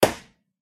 balloon_pop_02.ogg